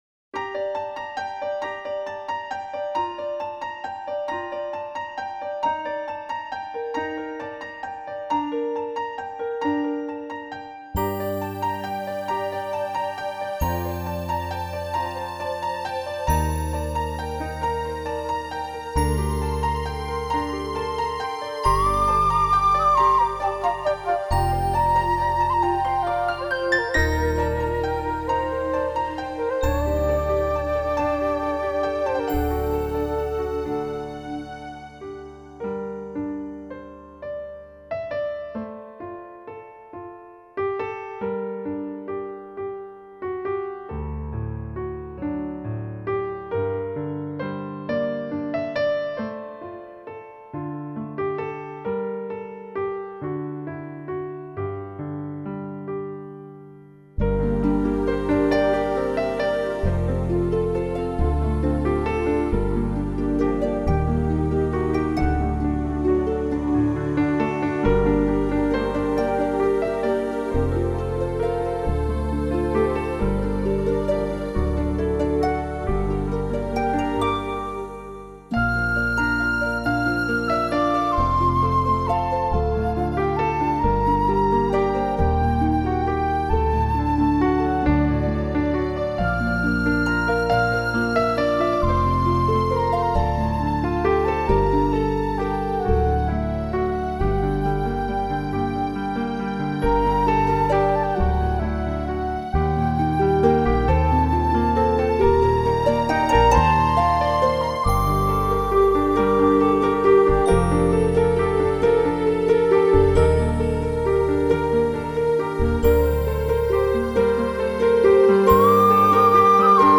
窥探大师内心的宁静音符。